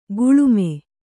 ♪ guḷume